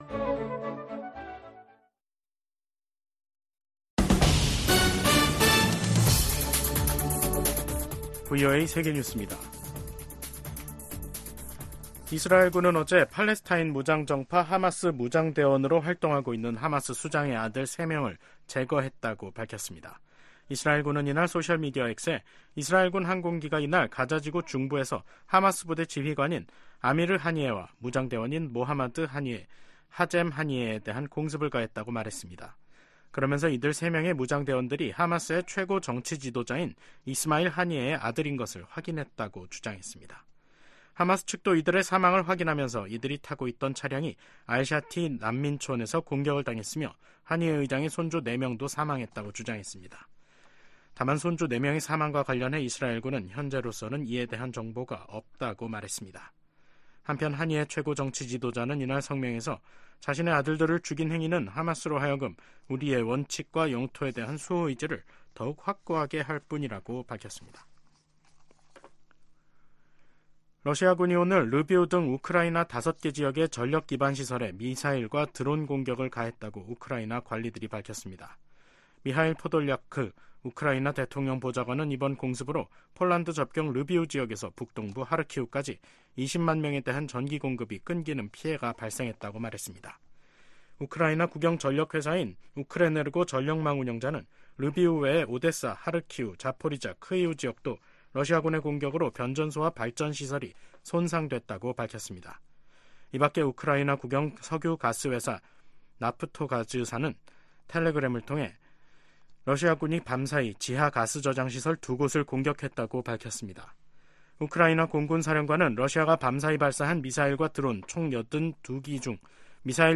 VOA 한국어 간판 뉴스 프로그램 '뉴스 투데이', 2024년 4월 11일 3부 방송입니다. 조 바이든 미국 대통령과 기시다 후미오 일본 총리가 정상회담을 열고 북한 문제와 군사 구조 재편 방안 등을 논의했습니다. 미국 상원과 하원에서 일본 총리의 미국 방문을 환영하는 결의안이 발의됐습니다. 어제 치러진 한국의 국회의원 총선거가 야당의 압승으로 끝난 가운데 탈북민 출신 4호 의원이 당선됐습니다.